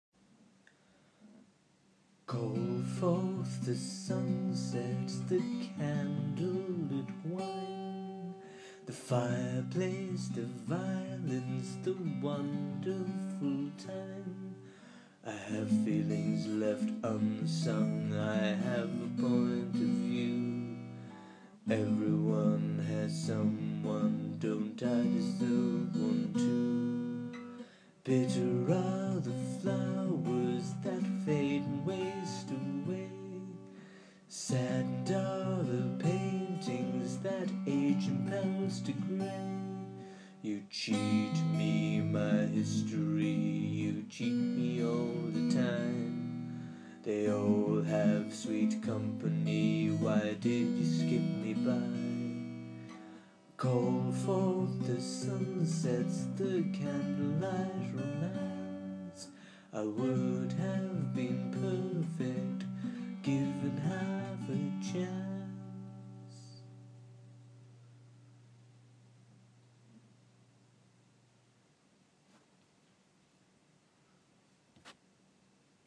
Oh dear, what a terribly depressing song.